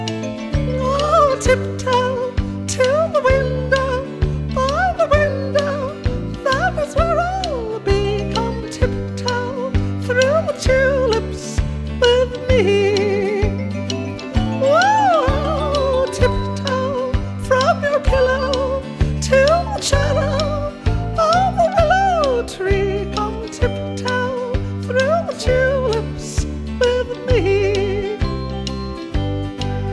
Жанр: Поп музыка / Рок / Соундтрэки